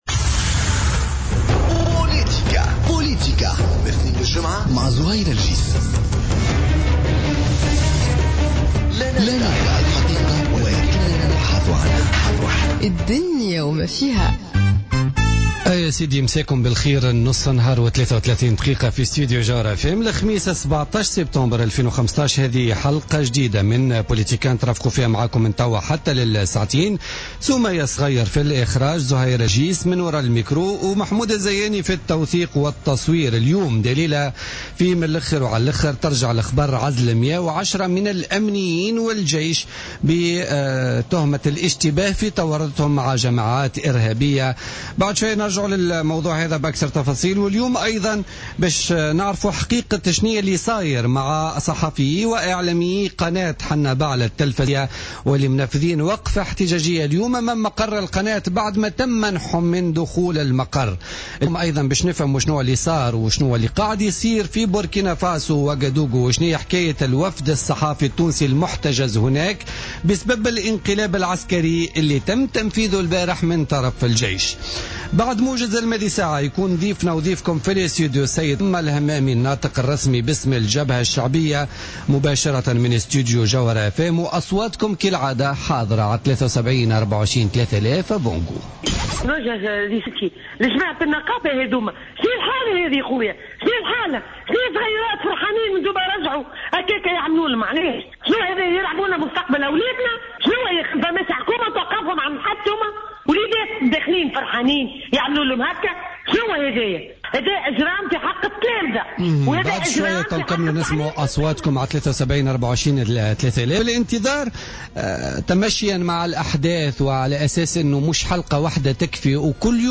Spéciale interview avec Hamma Hammami, porte-parole du Front Populaire